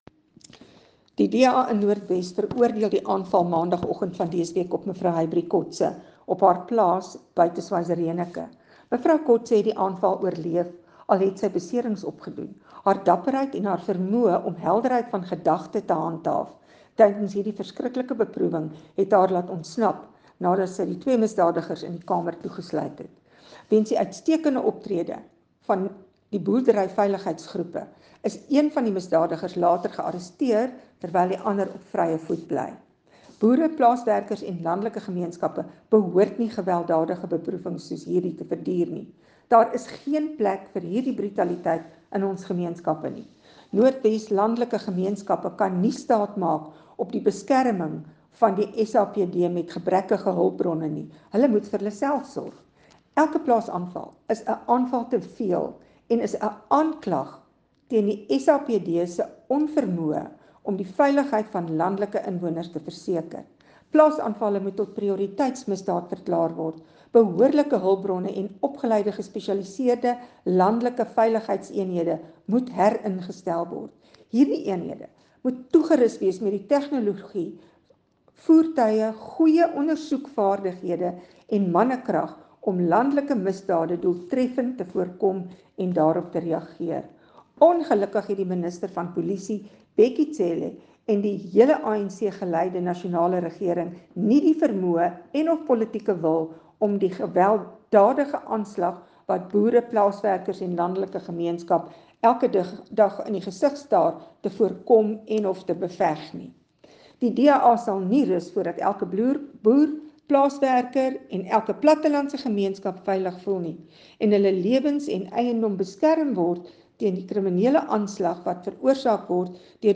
Note to Broadcasters: Please find linked soundbites in English and